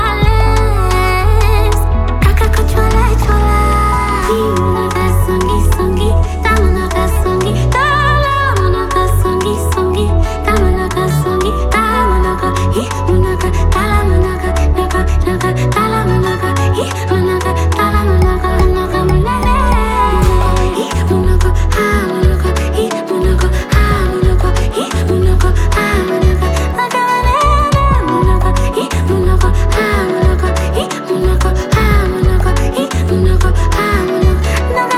Afro-Beat African Afro-Pop
Жанр: Поп музыка